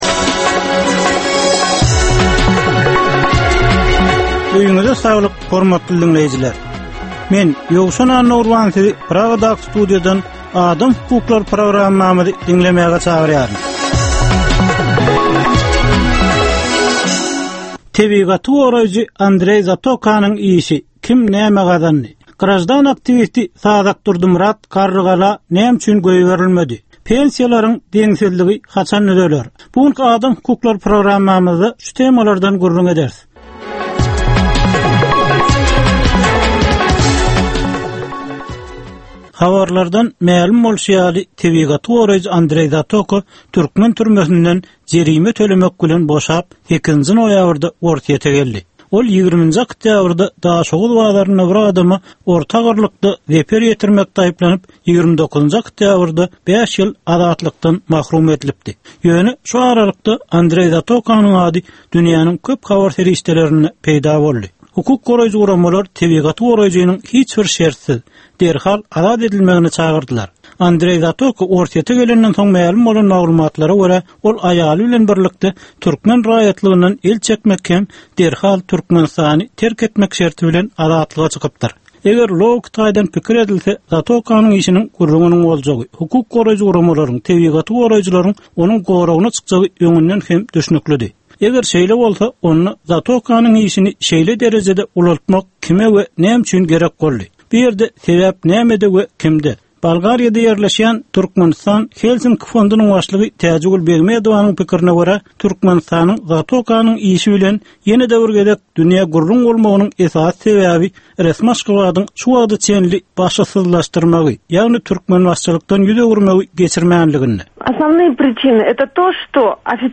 Türkmenistandaky ynsan hukuklarynyň meseleleri barada 15 minutlyk ýörite programma. Bu programmada ynsan hukuklary bilen baglanyşykly anyk meselelere, problemalara, hadysalara we wakalara syn berilýar, söhbetdeşlikler we diskussiýalar gurnalýar.